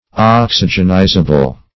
Oxygenizable \Ox"y*gen*i"za*ble\